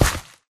gravel3.ogg